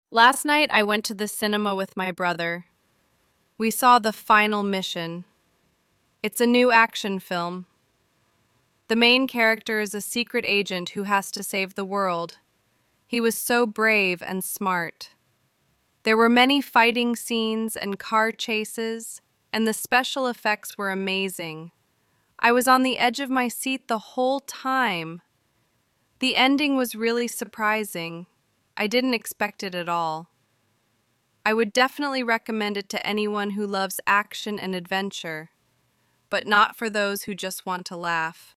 Part 1: You will hear a boy talking about a film he has just seen.